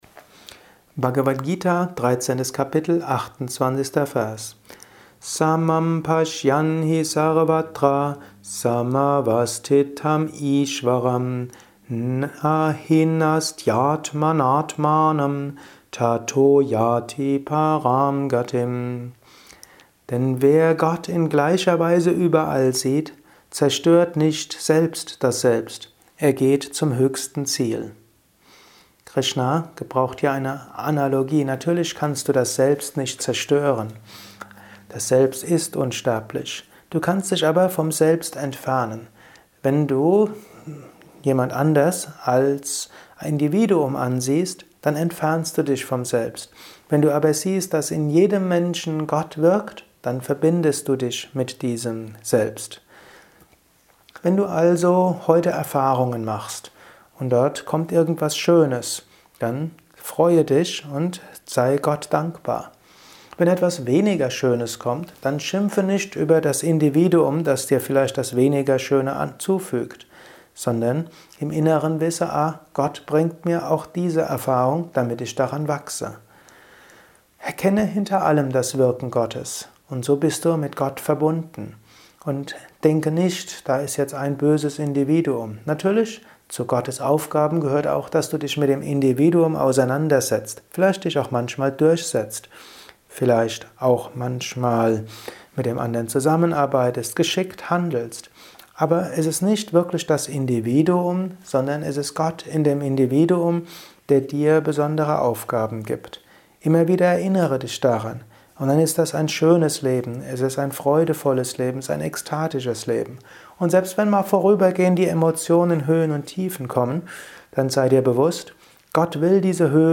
Dies ist ein kurzer Kommentar